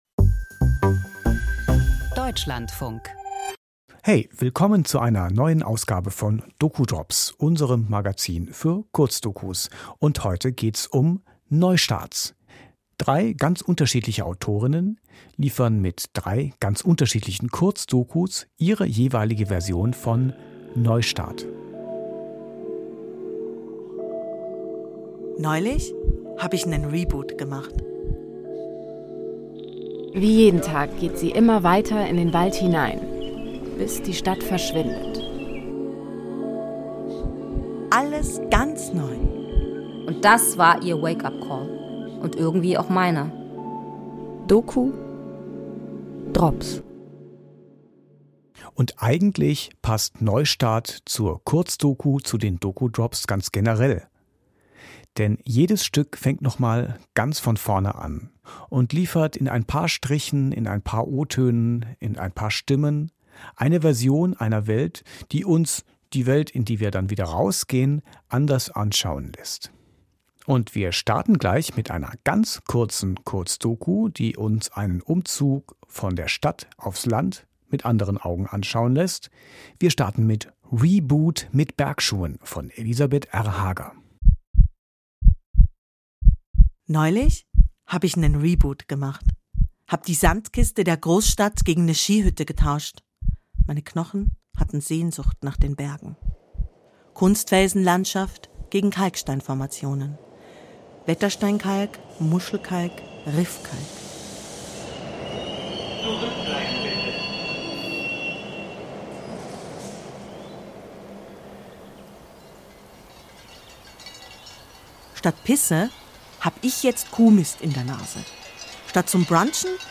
Feature Jeden Monat neue Kurzdokus doku drops 7: Neustart 27:59 Minuten Monatlich neu: die doku drops.